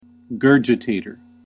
/ˈɡɝ.dʒə.teɪ.tɚ(米国英語), ˈɡɜː.dʒɪ.teɪ.tə(英国英語)/